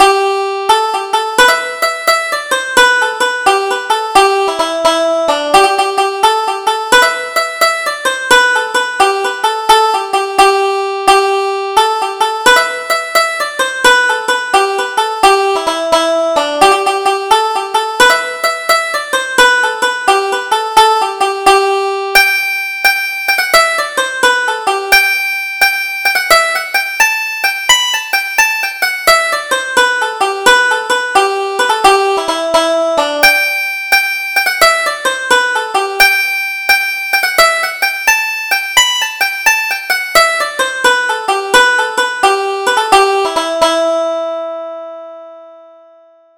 Double Jig: Our Own Little Isle